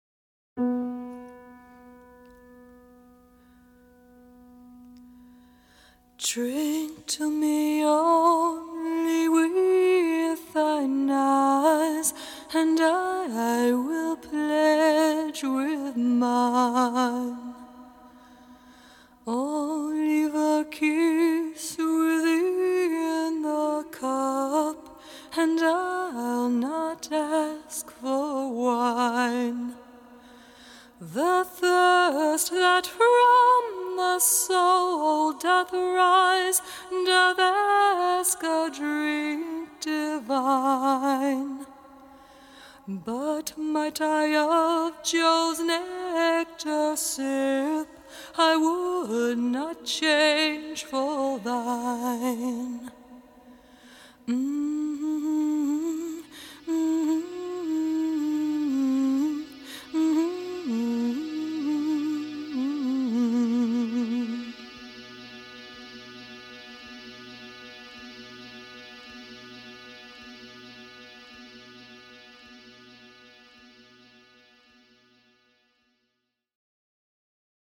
Experimental/Post-Punk/Industrial Rock
the use of peculiar instrumentation and droning vocals